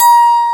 ZITHER.WAV